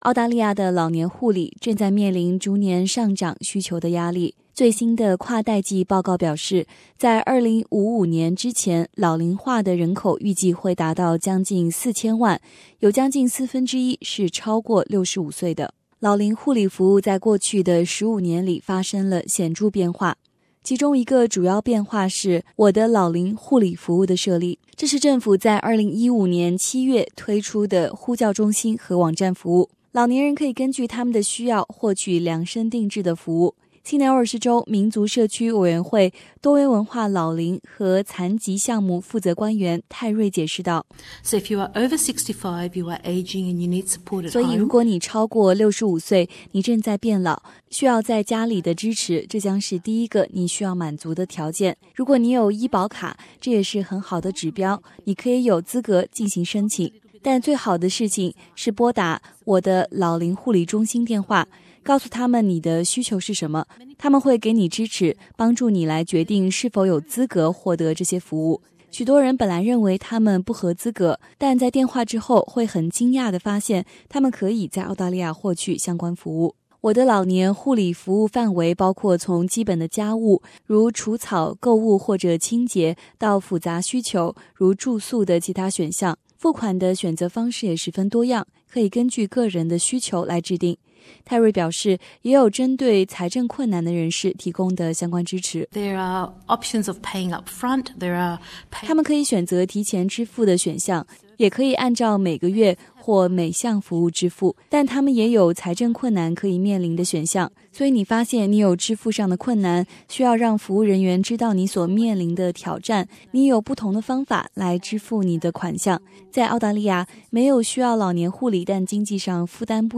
Elderly couple Source: getty image